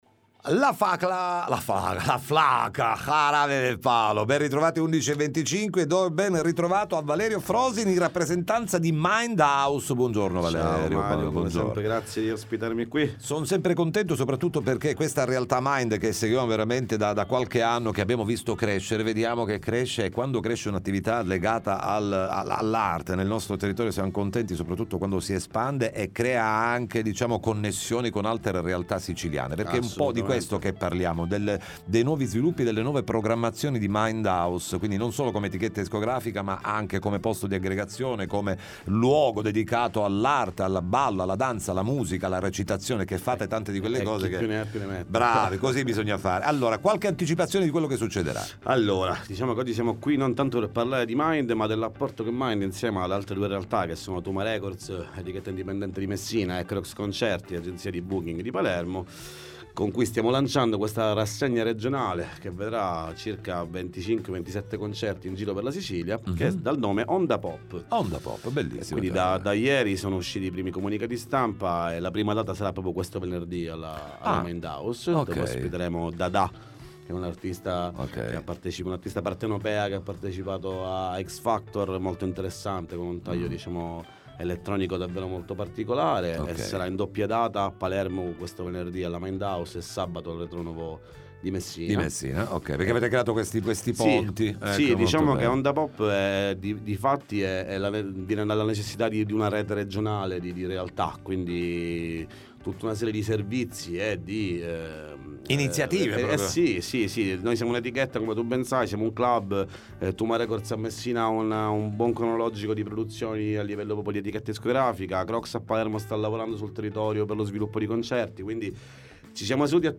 Programmazione spettacoli Mind House, ne parliamo con gli addetti ai lavori ai nostri microfoni